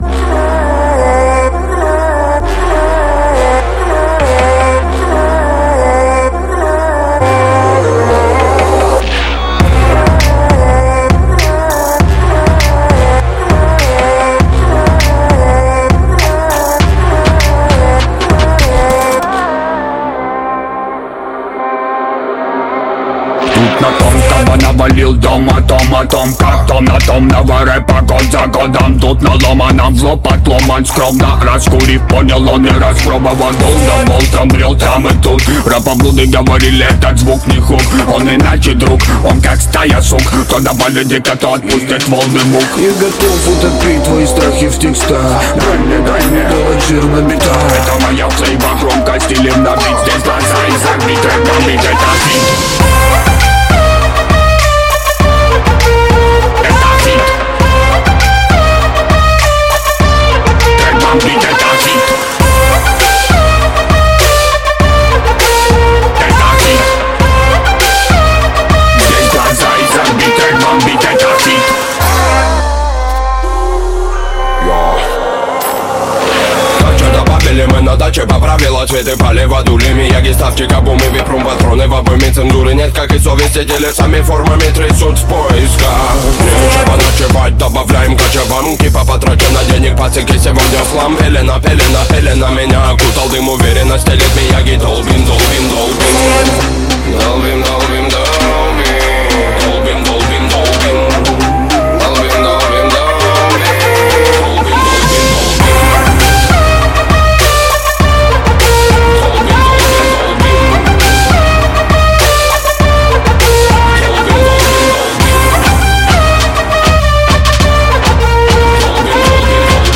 Жанр: Жанры / Хип-хоп